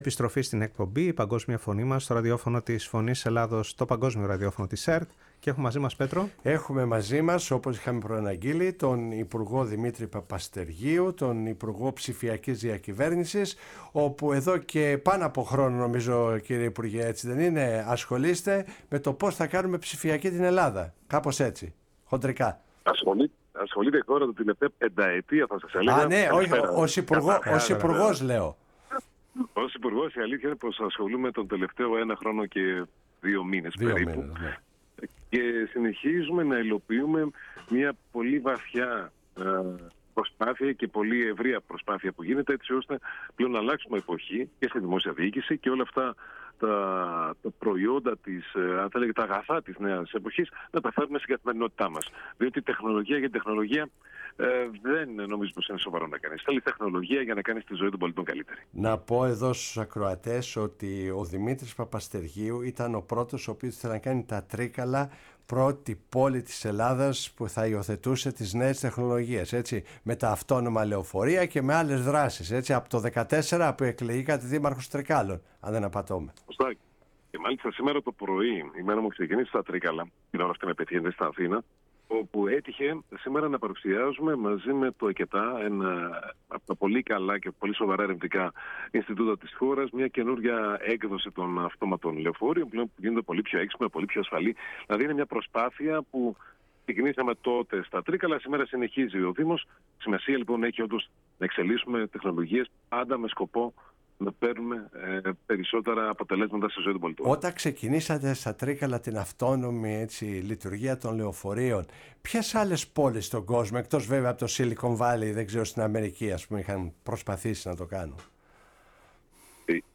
Η ΦΩΝΗ ΤΗΣ ΕΛΛΑΔΑΣ Η Παγκοσμια Φωνη μας ΣΥΝΕΝΤΕΥΞΕΙΣ Συνεντεύξεις ακινητα ΔΗΜΗΤΡΗΣ ΠΑΠΑΣΤΕΡΓΙΟΥ Τεχνητη Νοημοσυνη υγεια Υπουργος Ψηφιακης Διακυβερνησης